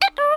spider_fall.mp3